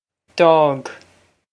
Pronunciation for how to say
D'awg
This is an approximate phonetic pronunciation of the phrase.